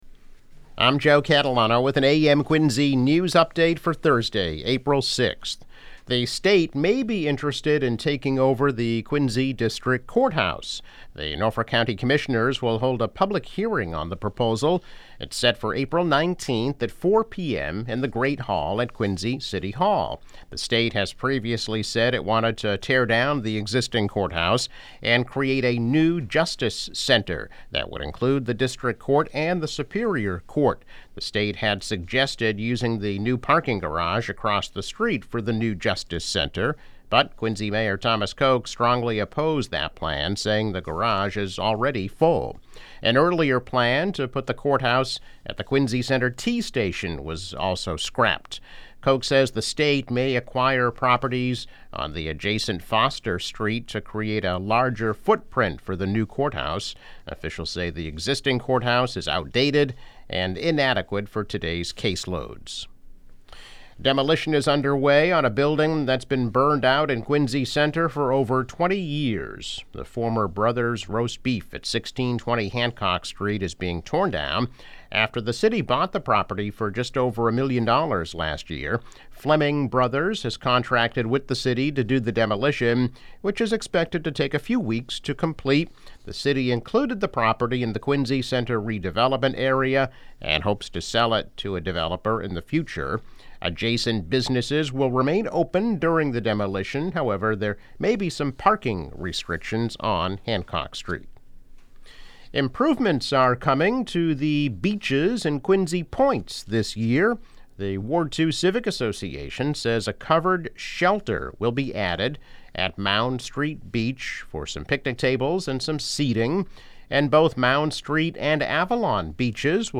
Daily news, weather and sports update.